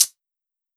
hihat 1.wav